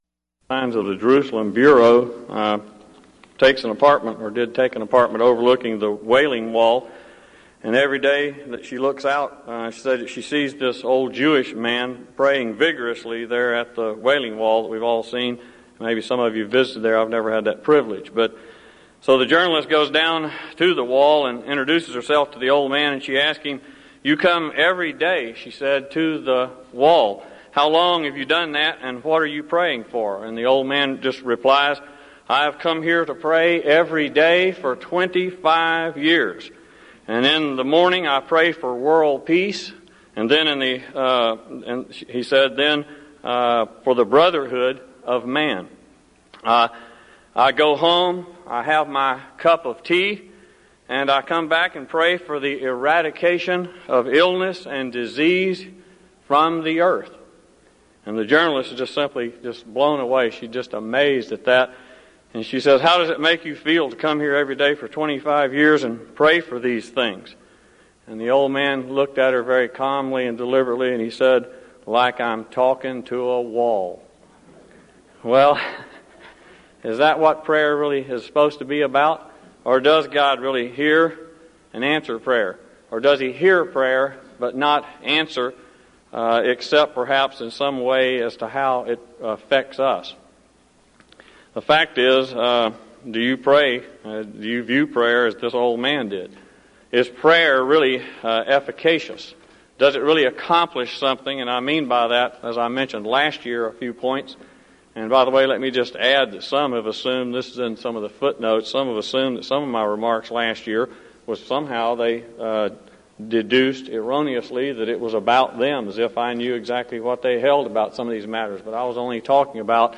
Title: DISCUSSION FORUM: Does God Really Hear And Answer Prayer?
Event: 1998 Denton Lectures